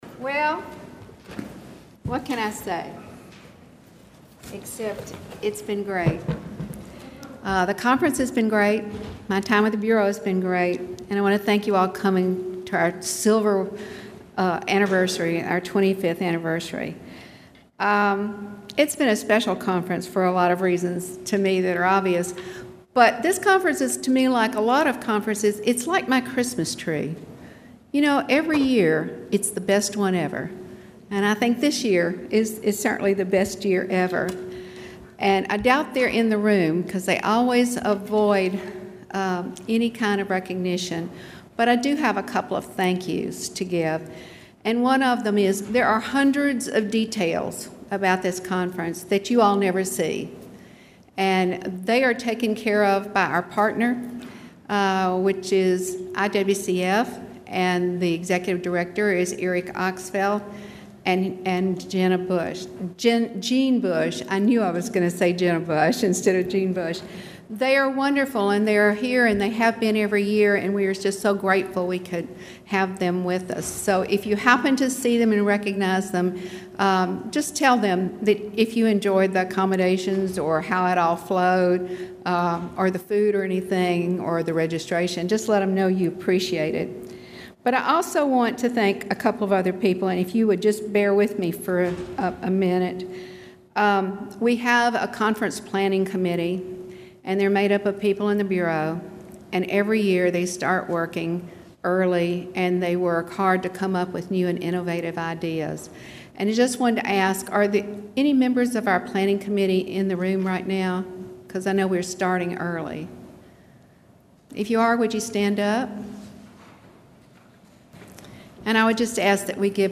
2022 TN Workers' Compensation Educational Conference
11:45 AM - 12:00 PM  in Mirabella F-J
Abbie Hudgens, Administrator, Tennessee Bureau of Workers’ Compensation